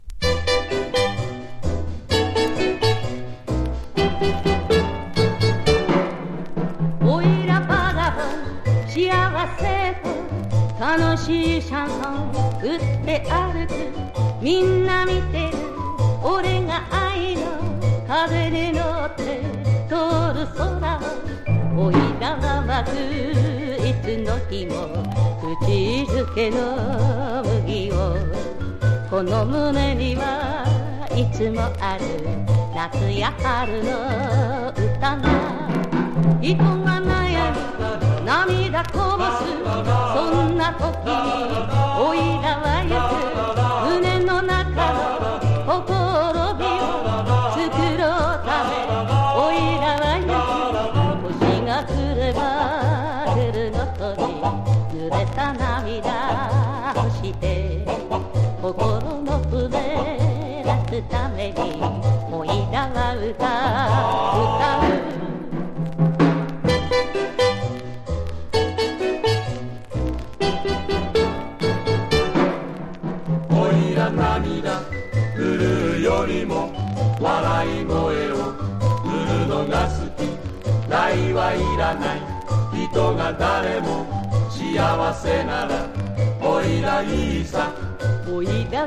和モノ / ポピュラー